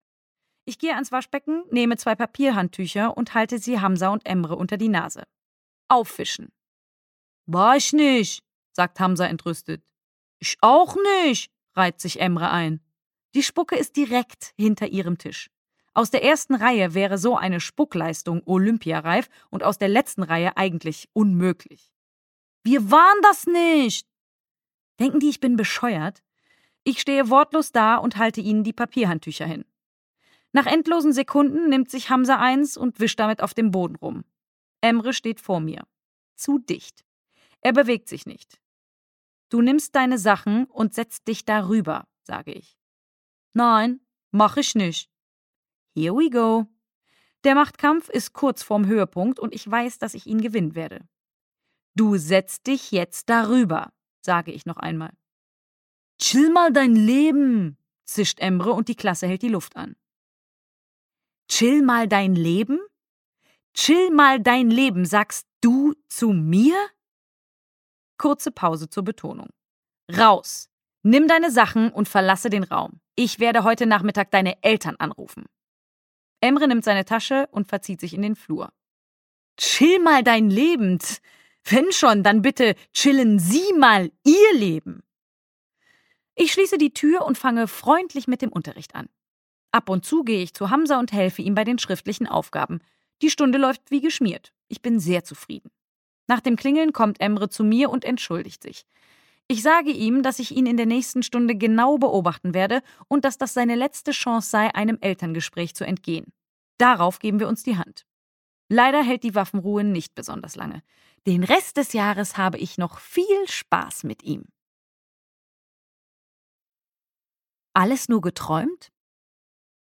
Echt easy, Frau Freitag! - Frau Freitag - Hörbuch